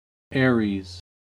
Ääntäminen
Synonyymit (harvinainen) Chèvre Mouton Ääntäminen : IPA: /be.lje/ Haettu sana löytyi näillä lähdekielillä: ranska Käännös Konteksti Ääninäyte Erisnimet 1.